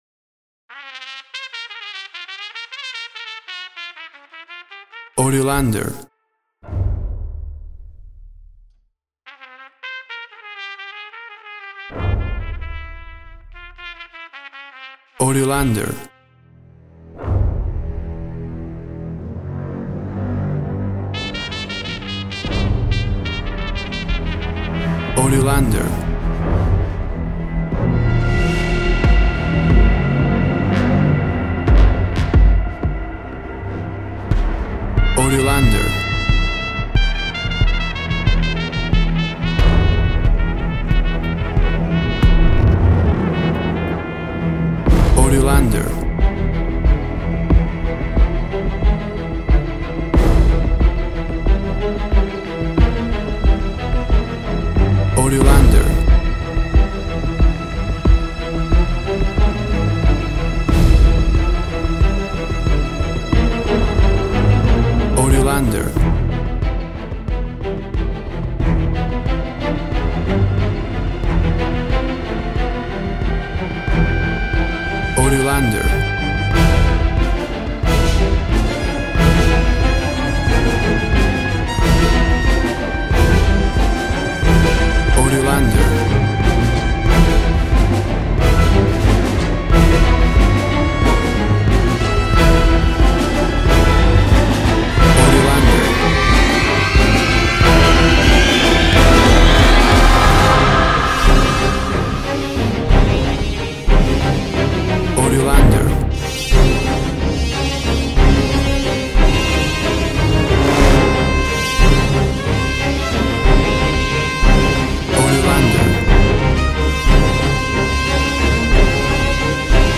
Sound surrealism, a little nod to tarantino?.
Tempo (BPM) 91